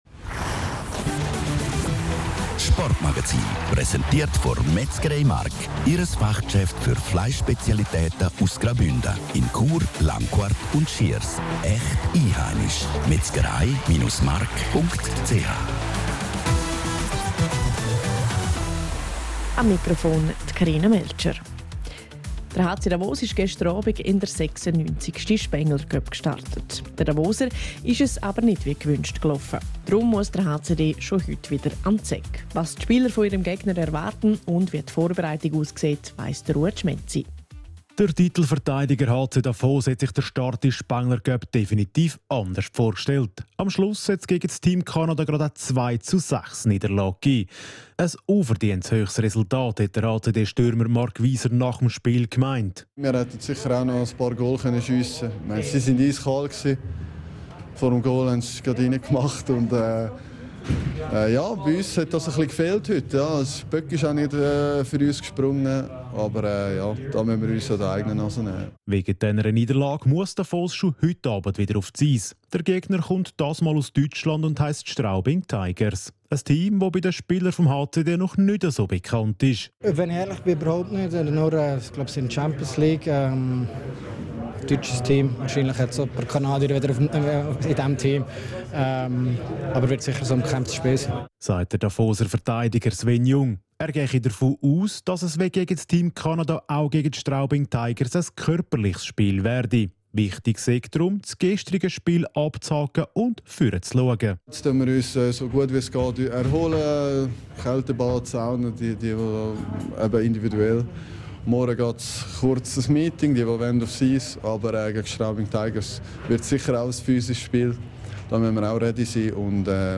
Nach der Niederlage gegen das Team Kanada muss gegen die Straubing Tigers ein Sieg her. Was die Spieler erwarten und wie die Vorbereitung aussieht, wir haben die Stimmen dazu.